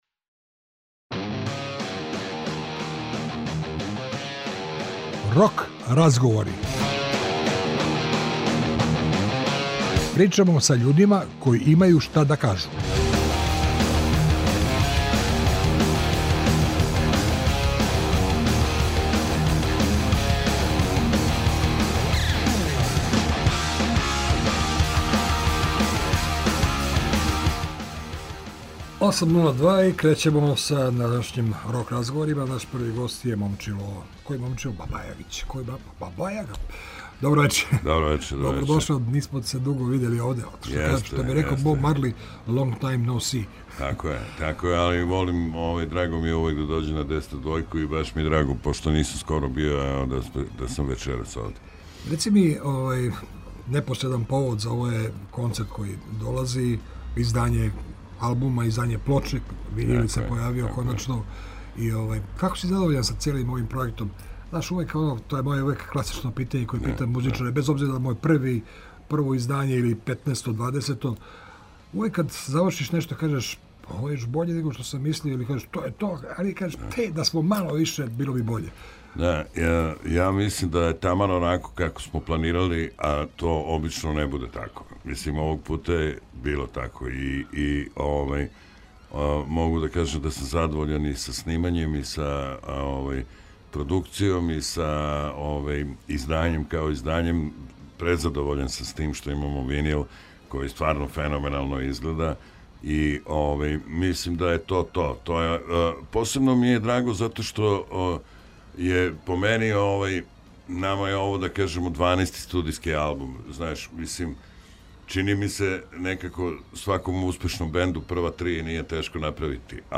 Овог четвртка нам у госте долази МОМЧИЛО БАЈАГИЋ БАЈАГА - поводом београдског концерта али и албума „У сали лом” који је недавно објављен на винилу у издању CROATIA RECORDS.